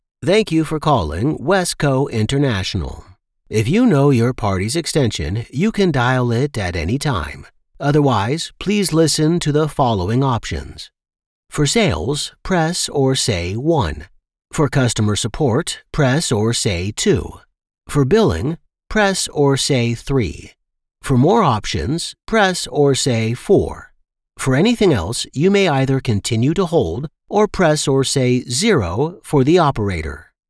Engels (Amerikaans)
Diep, Natuurlijk, Veelzijdig, Vertrouwd, Vriendelijk
Telefonie